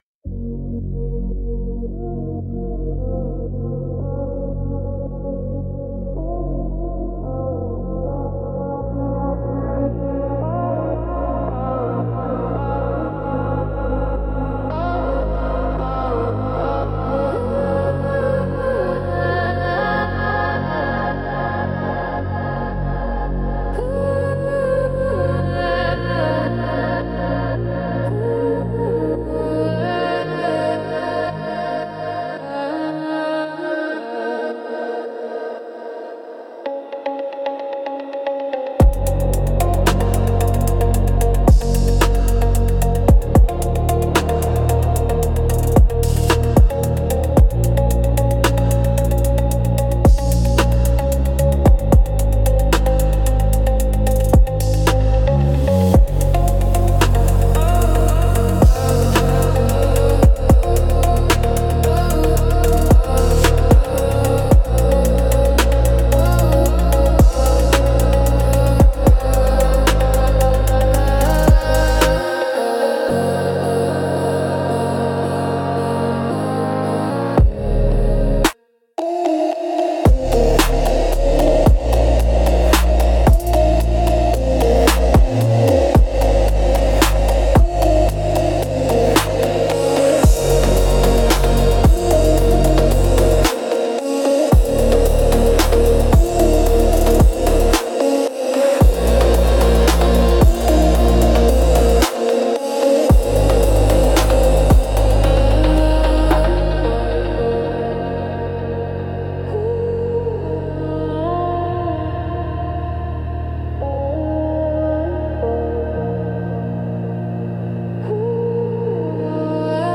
Instrumental - Through the Veil - 4.15